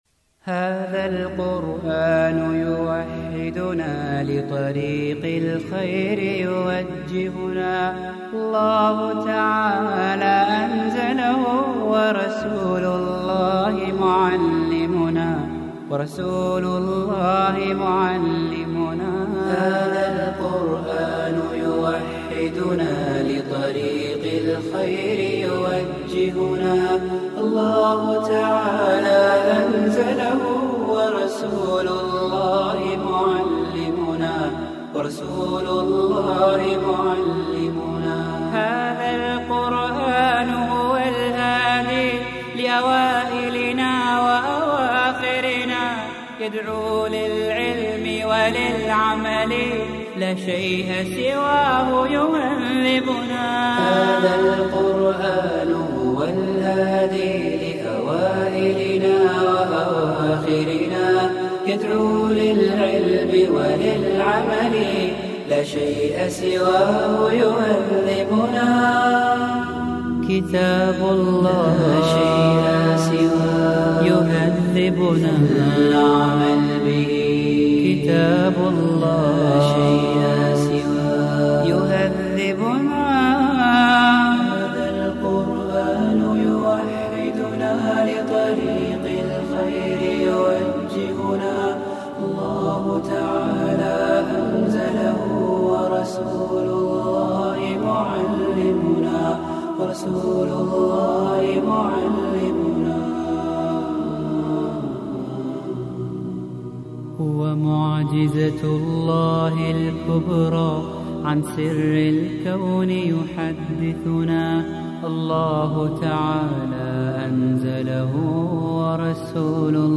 Arabic Islamic Songs